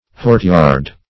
hortyard - definition of hortyard - synonyms, pronunciation, spelling from Free Dictionary Search Result for " hortyard" : The Collaborative International Dictionary of English v.0.48: Hortyard \Hort"yard\, n. An orchard.